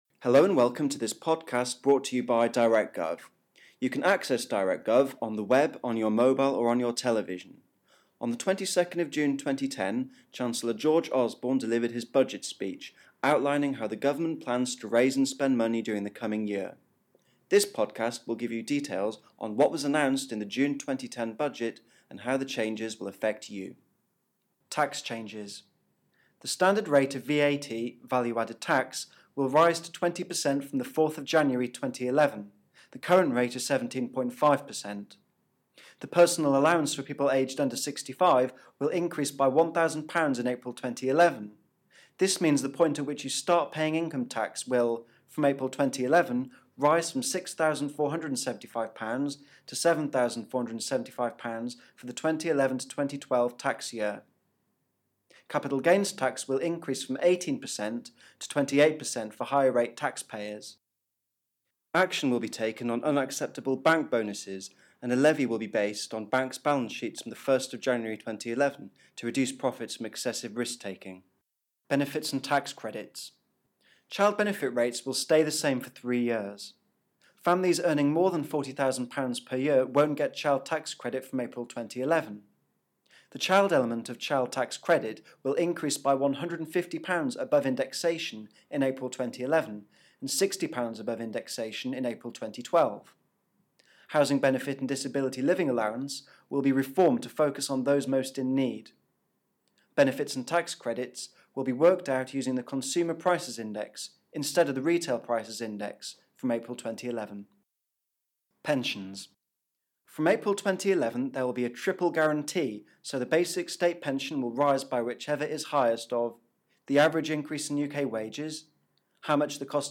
Chancellor George Osborne has delivered his Budget speech, outlining how the government plans to raise and spend money during the coming year.
Budget speech highlights